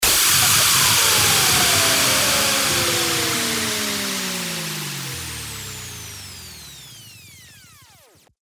FX-1813-WHOOSH
FX-1813-WHOOSH.mp3